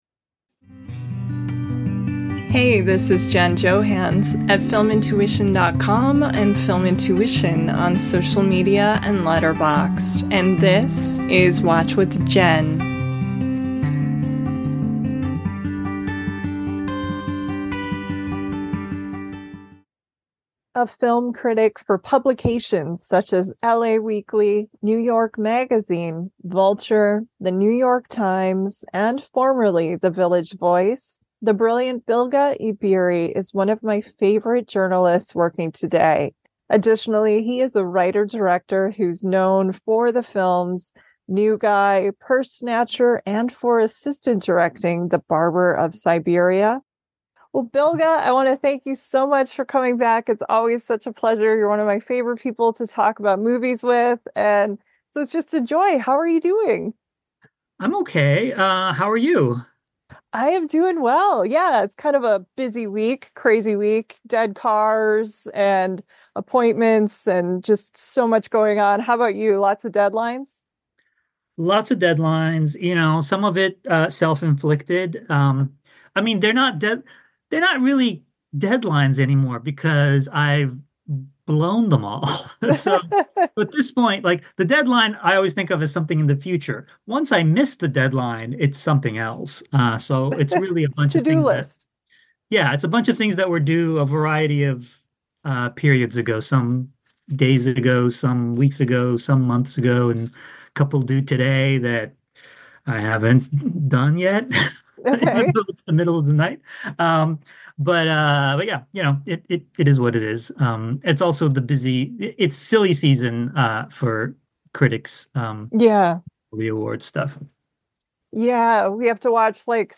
in this warm, chatty conversation